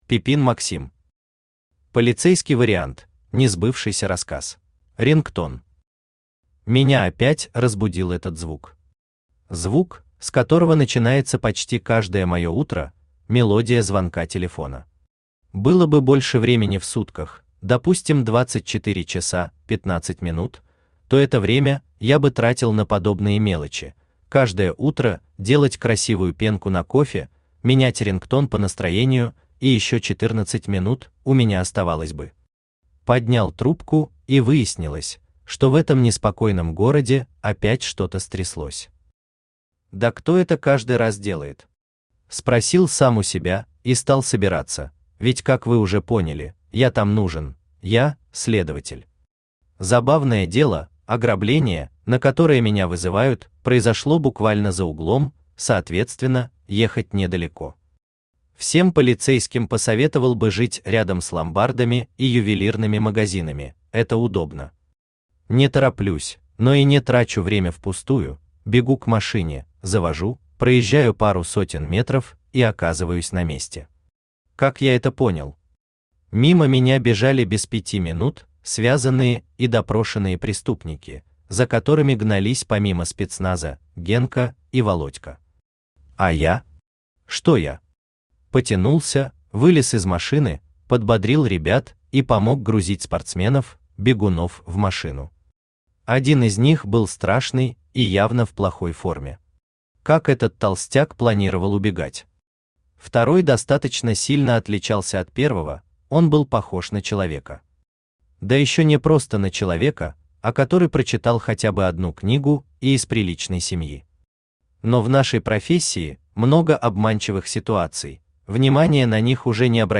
Aудиокнига Полицейский вариант: Несбывшийся рассказ Автор Пипин Васильевич Максим Читает аудиокнигу Авточтец ЛитРес.